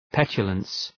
Προφορά
{‘petʃələns}